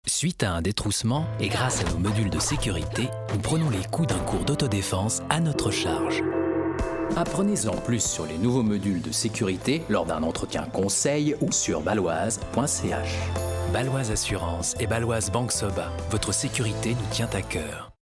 Francés (Suiza)
Precisa
Corporativo
Amistoso